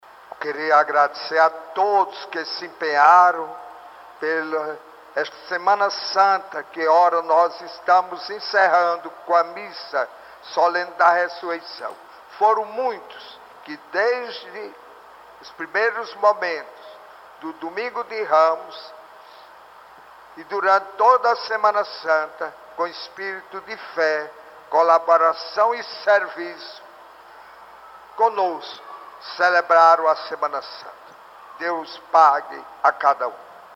A programação da Semana Santa 2019 foi encerrada nesta noite de domingo, 21, na Matriz de São Raimundo Nonato, Várzea Alegre.